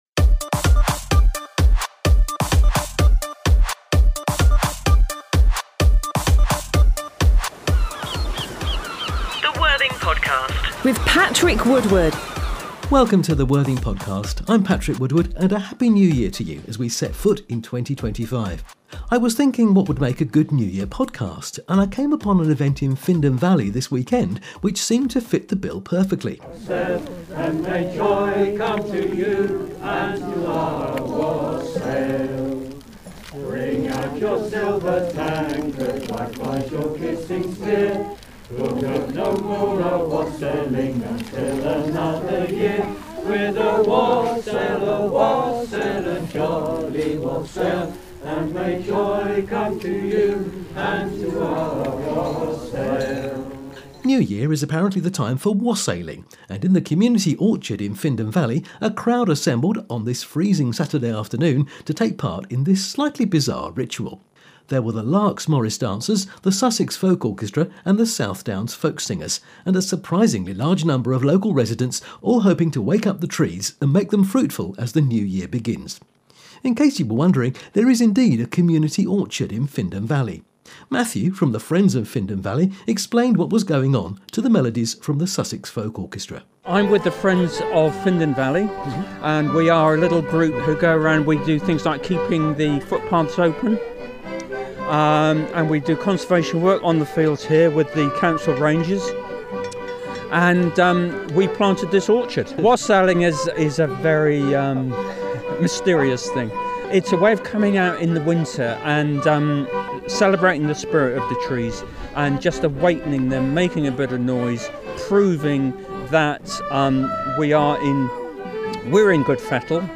A fun event to start off the year . A Wassail was held on Saturday 4th January at the Community Orchard in Findon Valley.